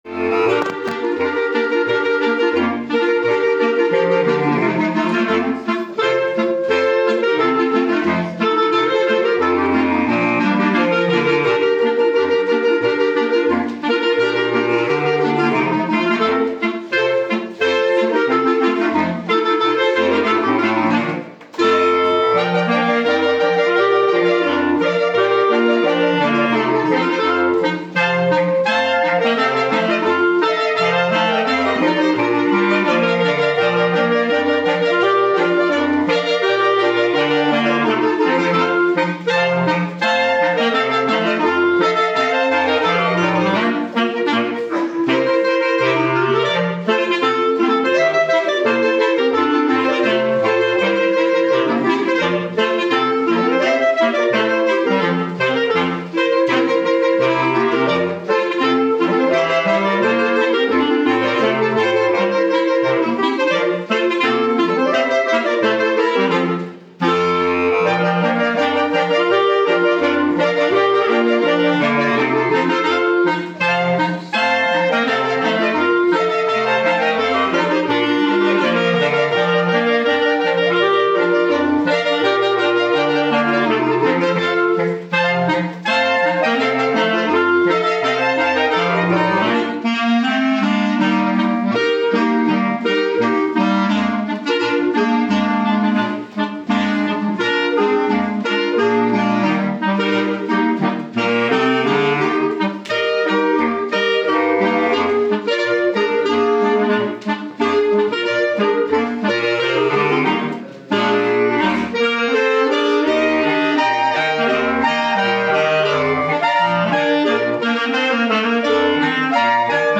private Aufnahme 2025